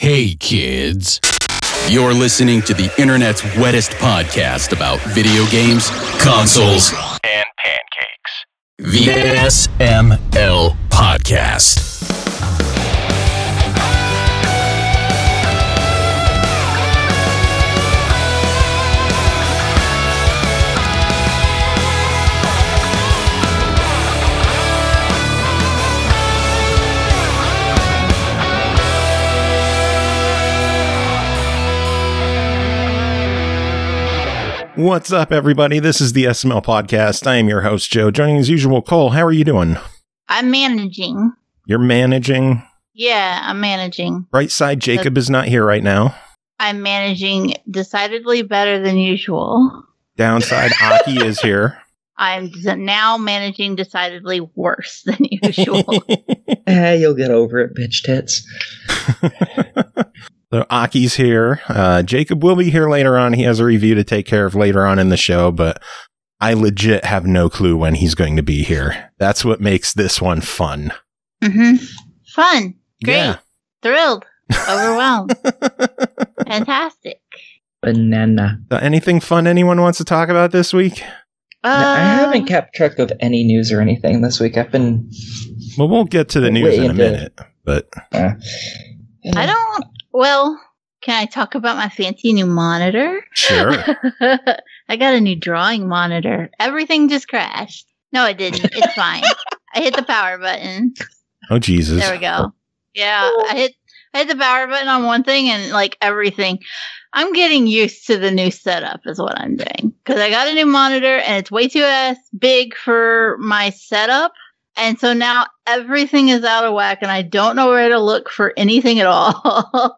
The show ends with some Viking Guitar Live to jam the night away!